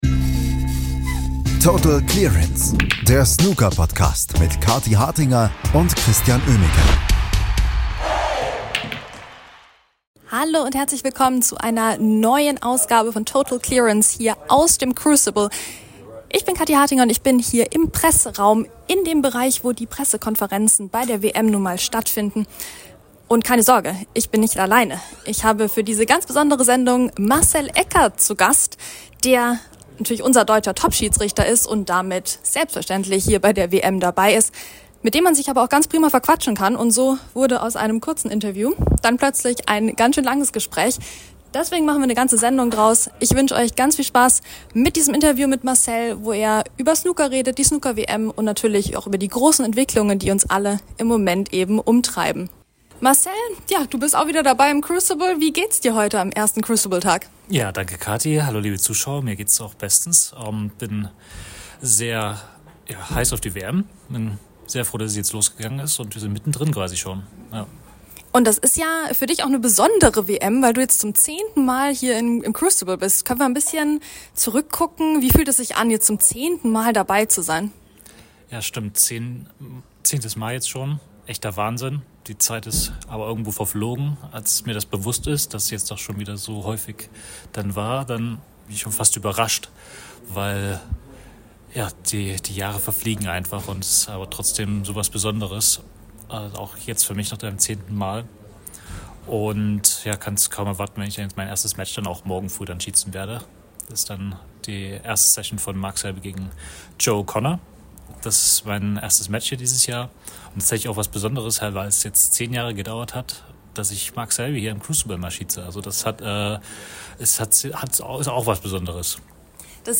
Es geht natürlich um die Weltmeisterschaft im Crucible, aber auch um die momentane Snooker-Entwicklung allgemein und viele weitere Themen. Hört einfach mal rein in das Interview mit dem deutschen Top-Referee!